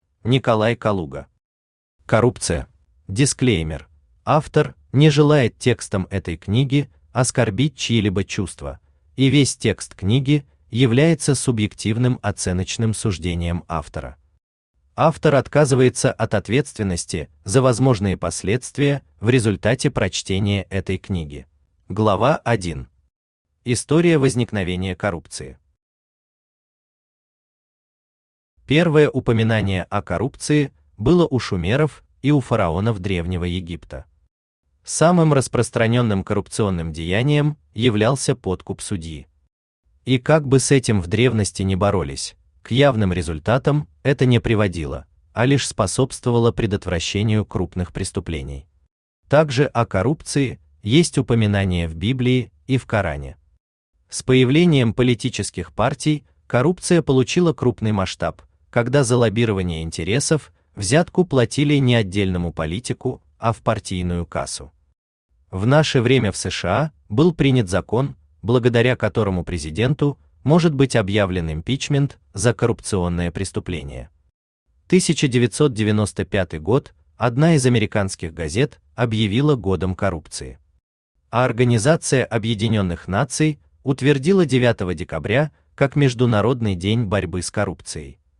Аудиокнига Коррупция | Библиотека аудиокниг
Aудиокнига Коррупция Автор Николай Владимирович Калуга Читает аудиокнигу Авточтец ЛитРес.